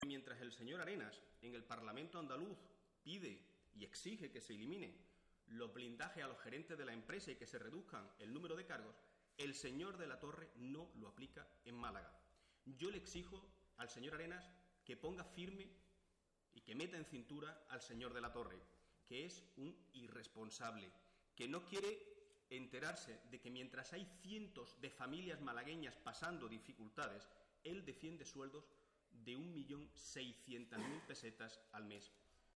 El secretario general del PSOE malagueño, Migue Ángel Heredia, ha pedido hoy en rueda de prensa al presidente del PP en Andalucía, Javier Arenas, que "ponga firme" a De la Torre, "un irresponsable, que no quiere enterarse que mientras hay cientos de familias malagueñas que pasan dificultades, él defiende sueldos de 1,6 millones de pesetas al mes".